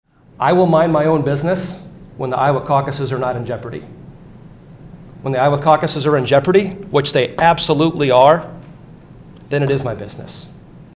Bill sponsor Representative Bobby Kaufmann, the son of Iowa GOP chairman Jeff Kaufmann, responded to Nielsen.